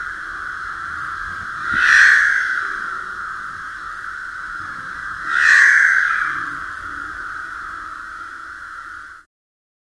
Еще один рёв медведя